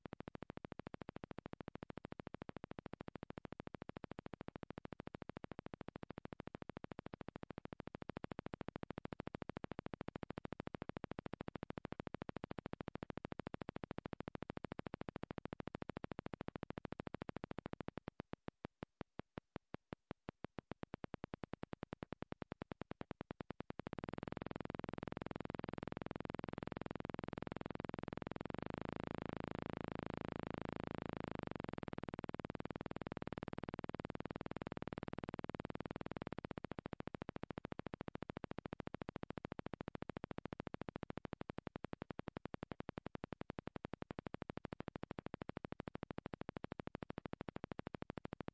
火炬 擦洗1
描述：火炬塑料火灾声音环境自然周围现场记录环境裂纹气氛噪音磨砂 环境foley录音和实验声音设计。
标签： 声音 擦洗 气氛 环境 周围 环境 塑料 裂纹 火炬 现场记录 天然 噪声
声道立体声